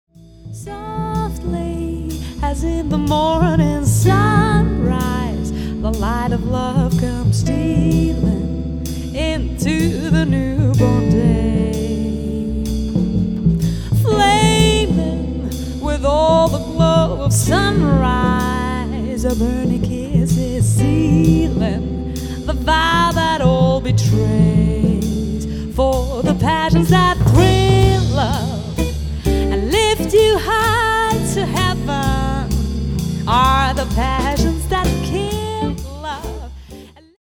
Jazz Sängerin & Songwriterin